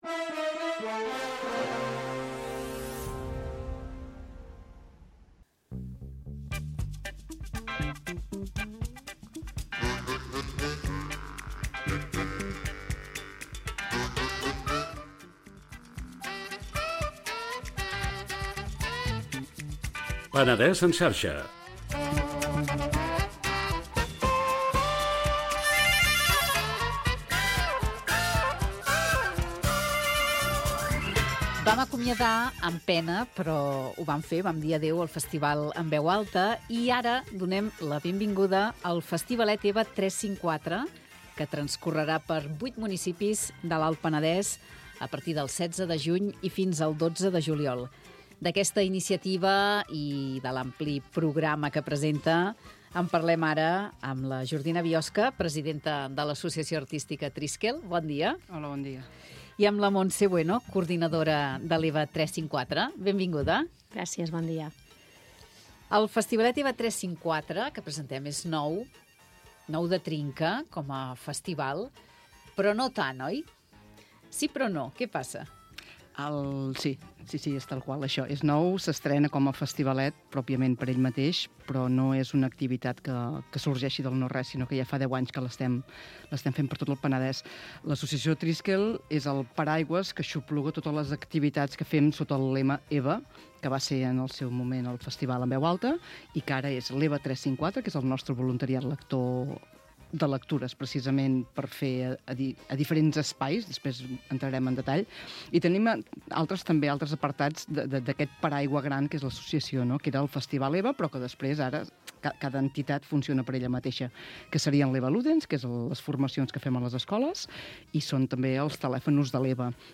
Entrevista a Ràdio Vilafranca: al «Penedès en Xarxa»